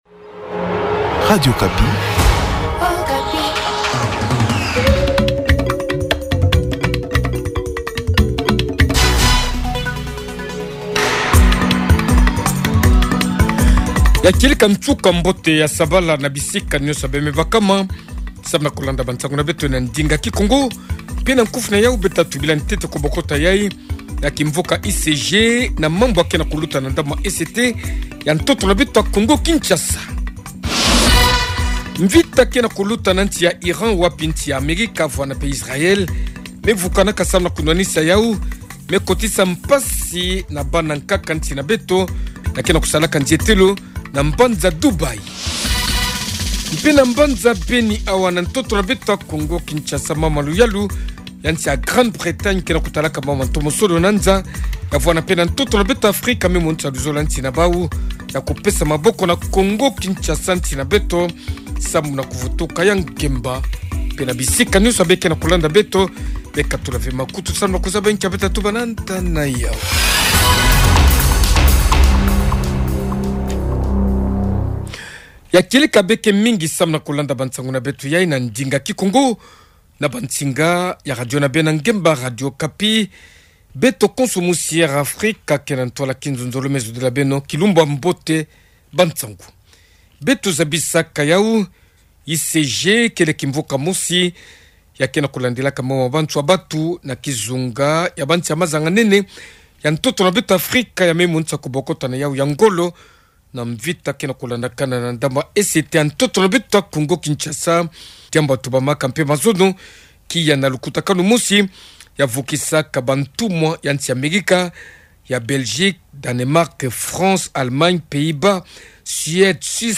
Journal Soir
Edition de ce soir  18h30''